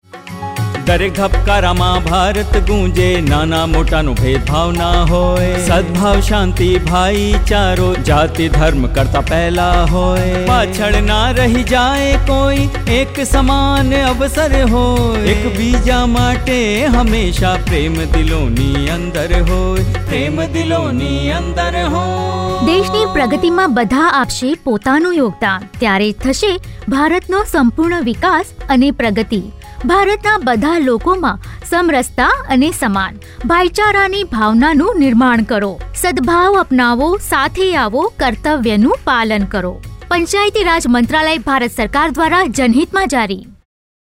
62 Fundamental Duty 5th Fundamental Duty Sprit of common brotherhood Radio Jingle Gujrati